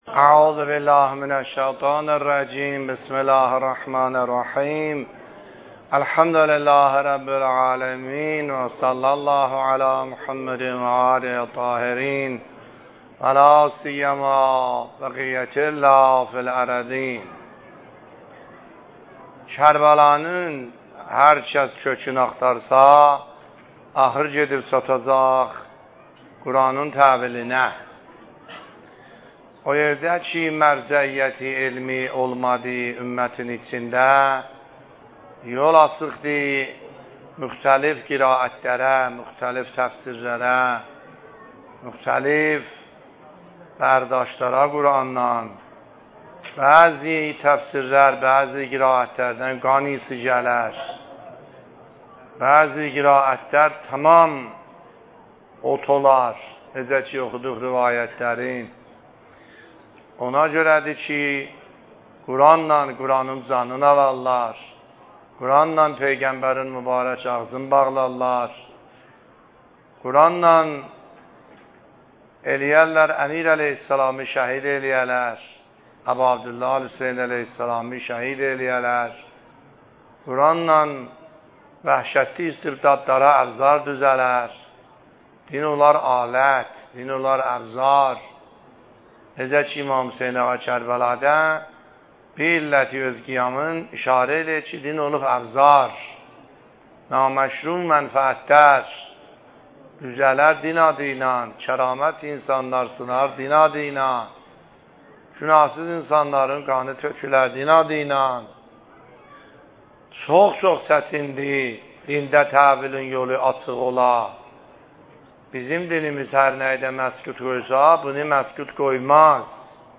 سخنرانی آیه الله سیدحسن عاملی فایل شماره ۶ - دهه اول محرم ۱۳۹۷